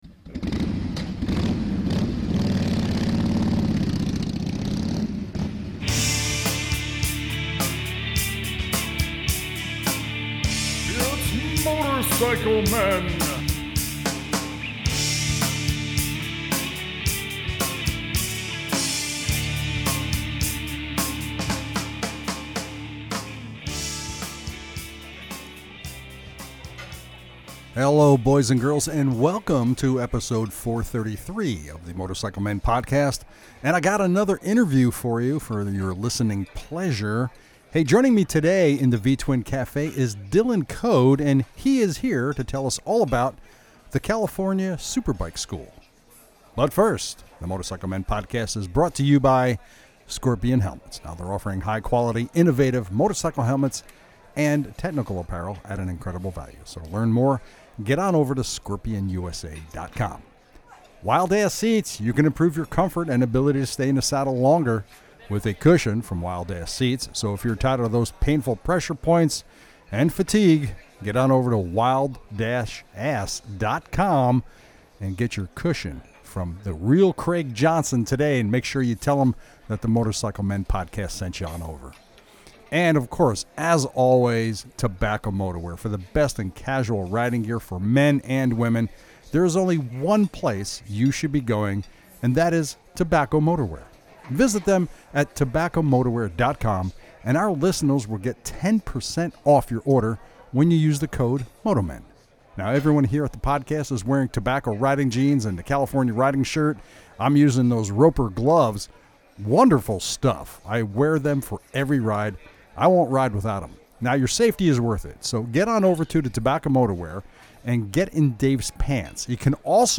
Episode 433 - Interview